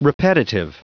Prononciation du mot repetitive en anglais (fichier audio)
Prononciation du mot : repetitive